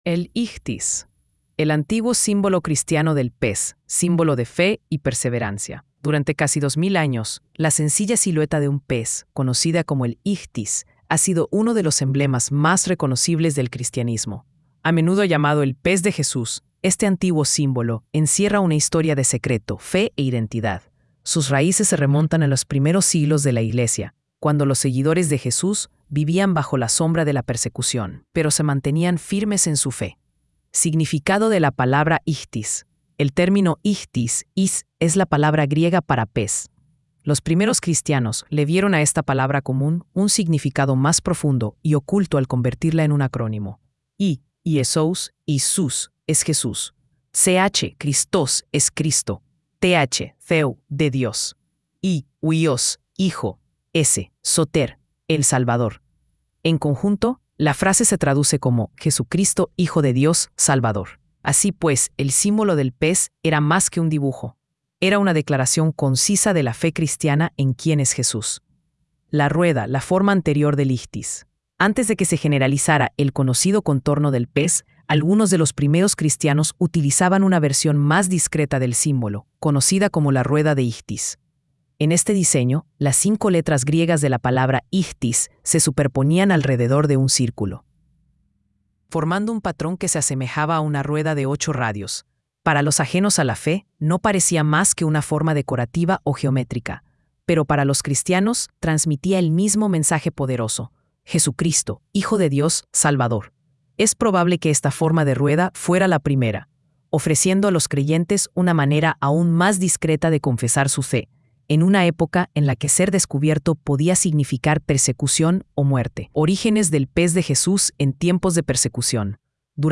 El Secreto del Pez (Ichthys)” es una hermosa  balada de adoración que revive el antiguo símbolo cristiano del pez —el Ichthys—, emblema de fe secreta y esperanza eterna en tiempos de persecución. Con un tono íntimo y reverente, la canción viaja desde la sombra hasta la revelación, desde la clandestinidad de los primeros creyentes hasta la proclamación abierta del Evangelio.